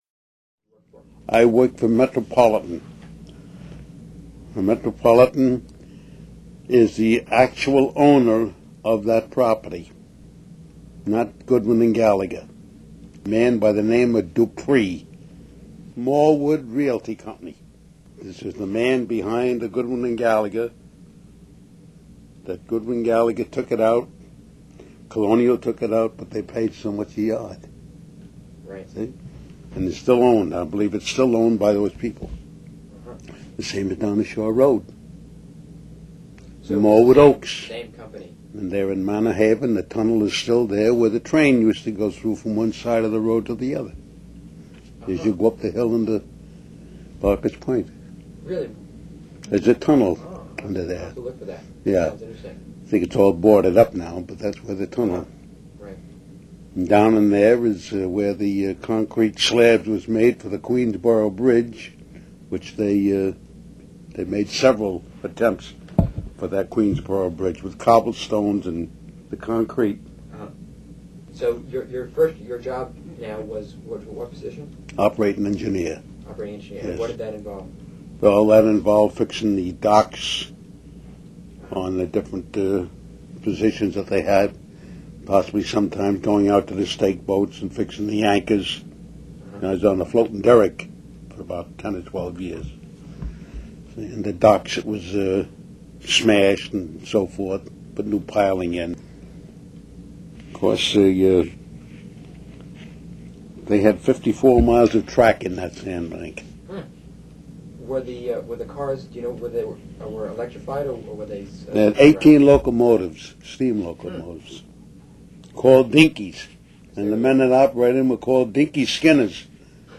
The following interview is one of a series of tape-recorded memoirs in the Port Washington Public Library Community Oral History Program.